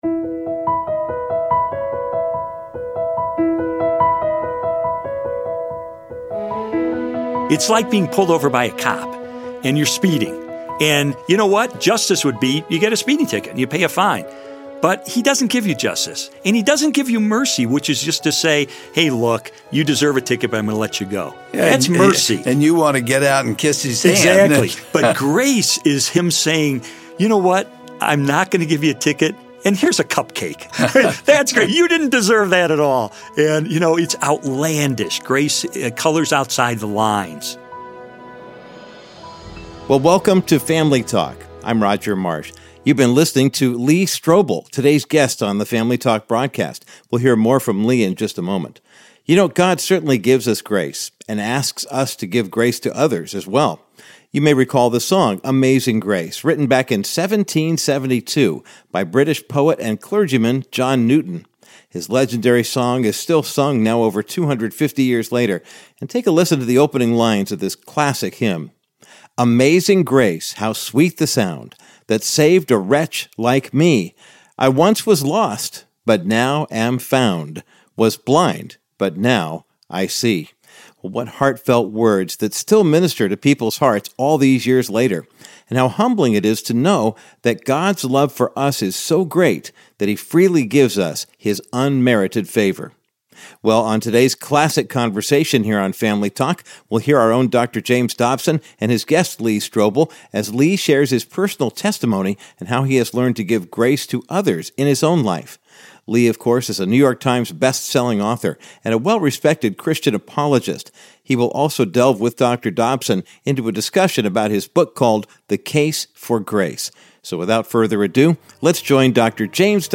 On today’s edition of Family Talk, Dr. James Dobson and best-selling author and Christian apologist, Lee Strobel, observe how grace is central to the Christian faith. Lee also shares his personal testimony about receiving this most undeserved gift from God.
Host Dr. James Dobson